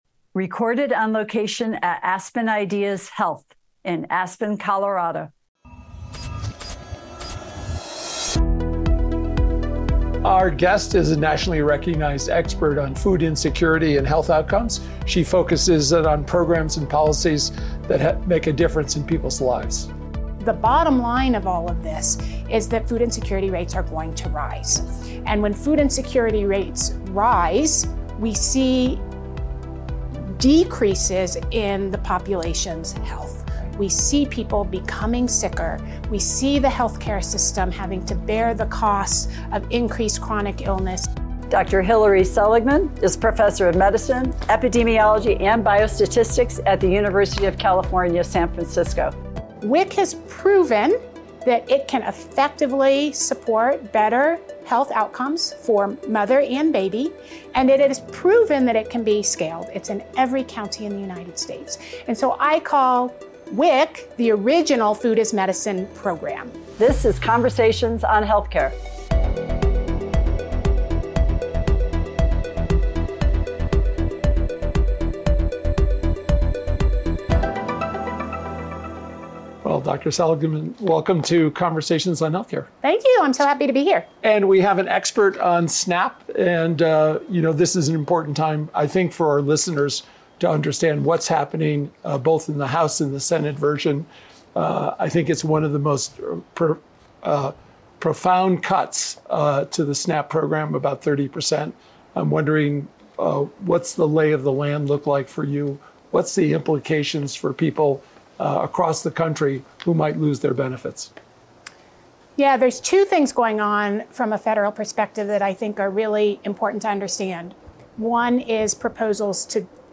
Click to hear this conversation, recorded during the Aspen Ideas: Health conference.